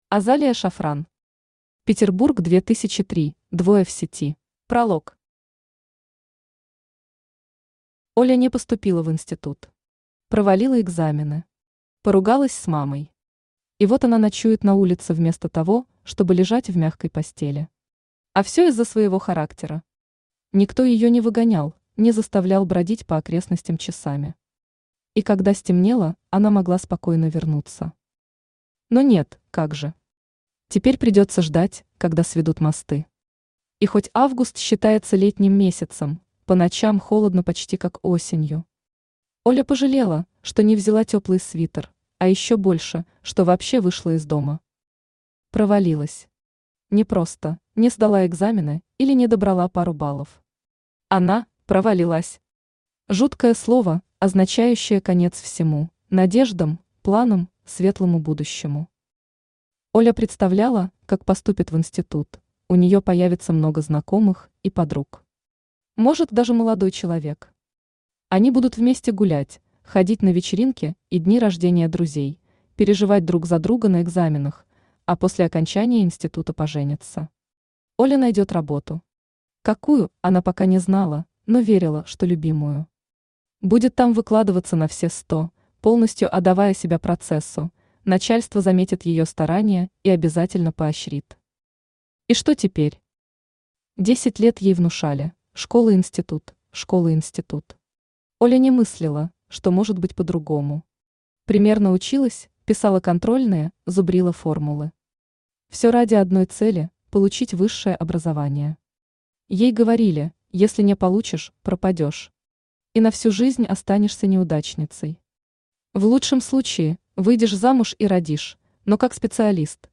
Аудиокнига Петербург 2003: двое в сети | Библиотека аудиокниг
Aудиокнига Петербург 2003: двое в сети Автор Азалия Шафран Читает аудиокнигу Авточтец ЛитРес.